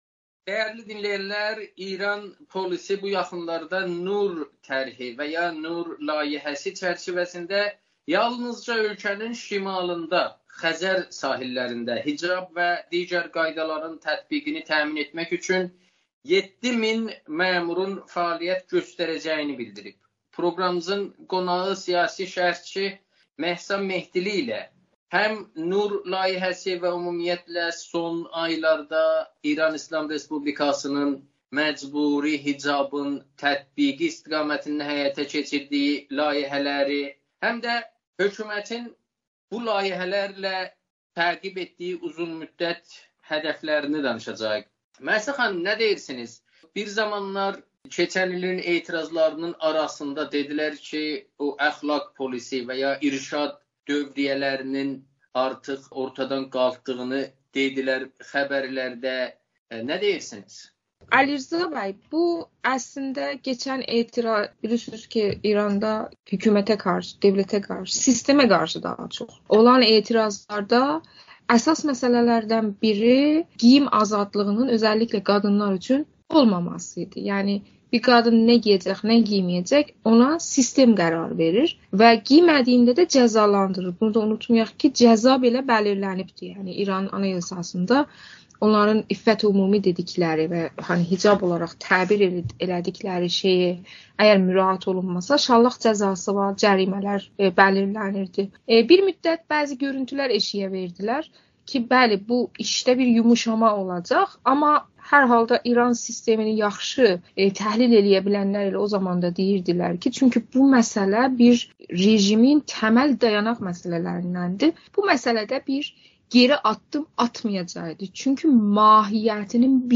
müsahibədə